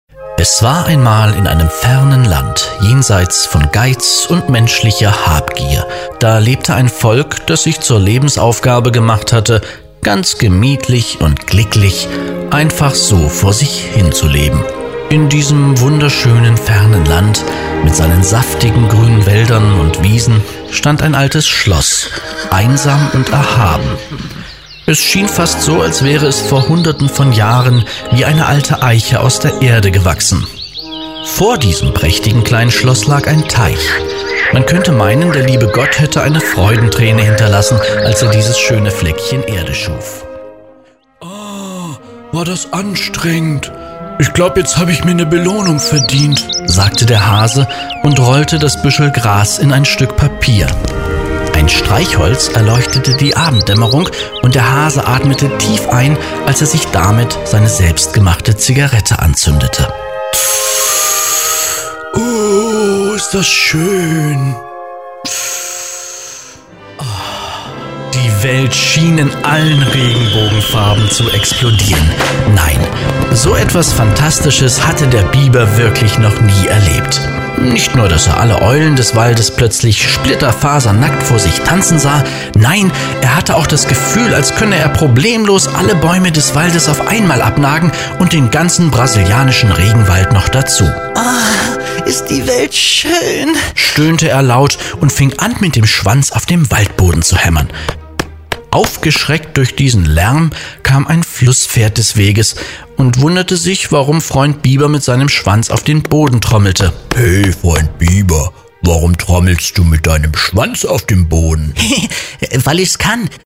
Professioneller Sprecher für Werbung, Präsentationen, e-learning, Funk, Film, TV & Kino, Werbespots, Voice Over, Multimedia, Internet, Industriefilm,
Sprechprobe: eLearning (Muttersprache):
german voice over artist